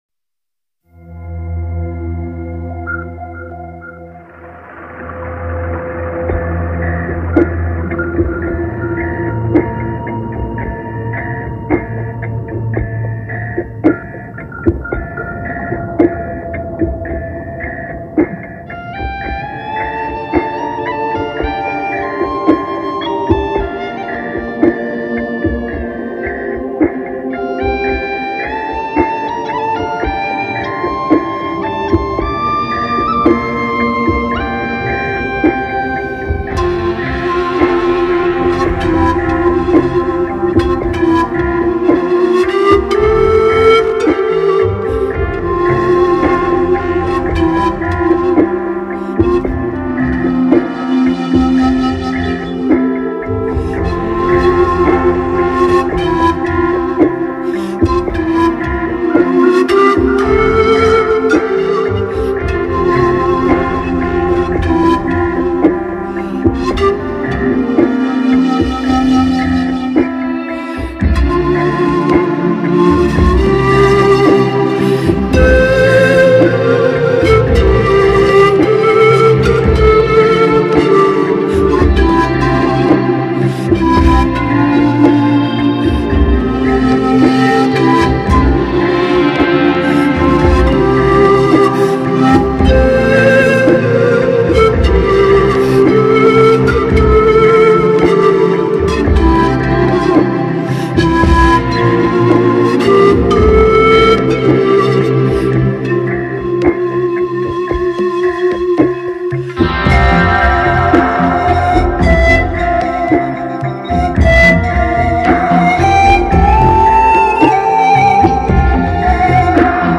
世界首席女性排笛演奏家